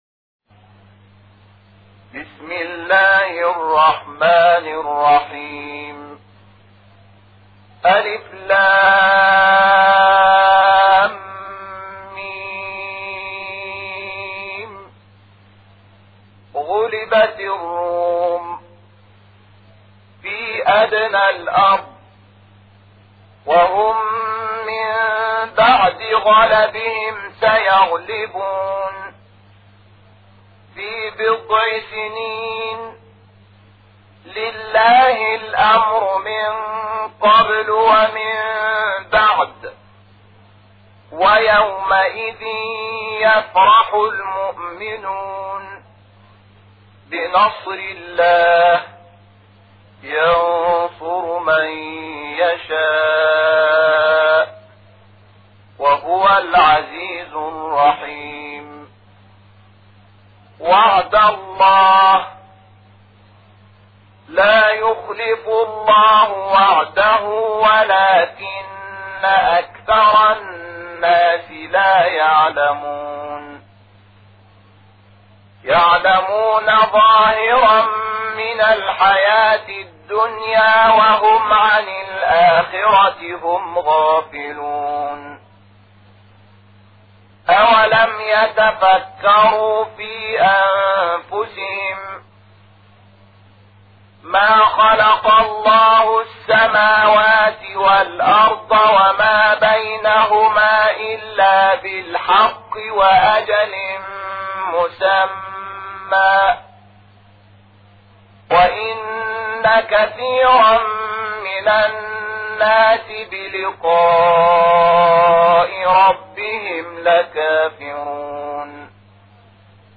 صوت | کرسی تلاوت مجازی قرآن ویژه شب‌های قدر با صوت قاریان مصری